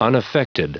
Prononciation du mot unaffected en anglais (fichier audio)
Prononciation du mot : unaffected